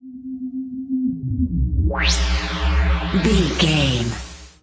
Dark To Bright Appear
Sound Effects
Atonal
magical
mystical